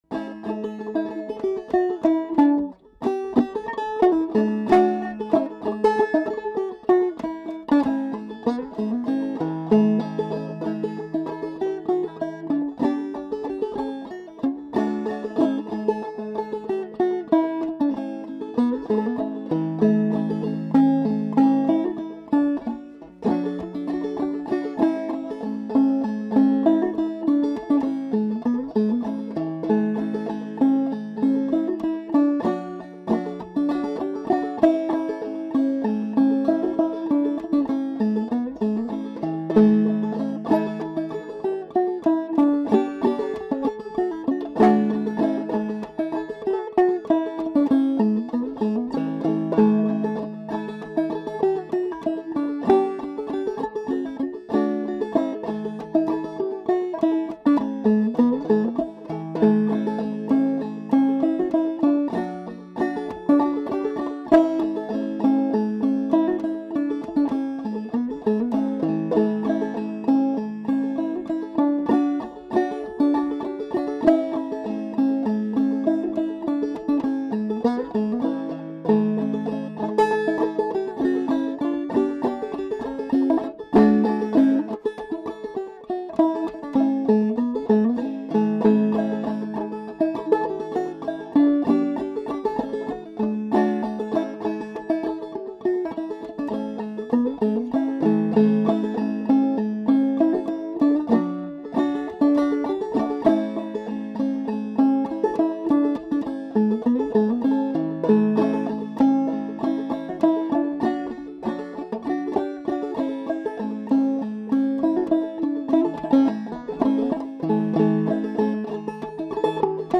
Clawhammer Banjo Podcasts